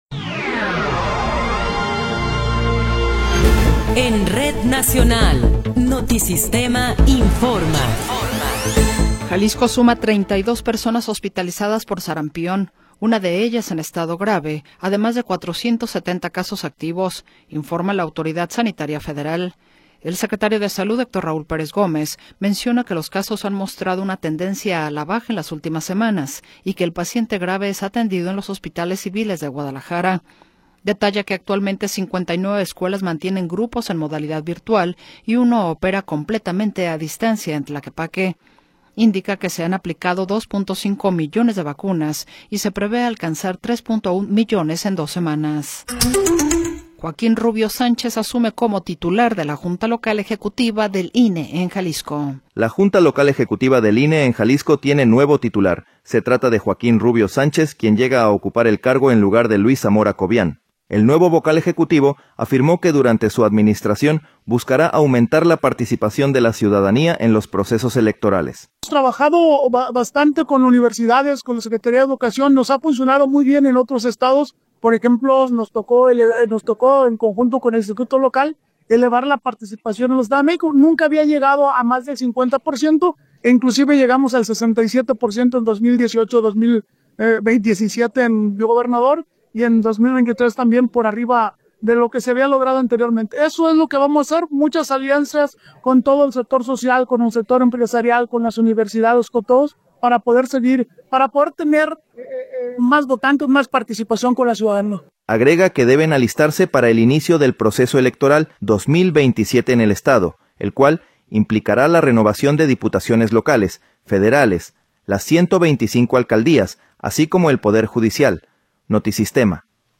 Noticiero 16 hrs. – 2 de Marzo de 2026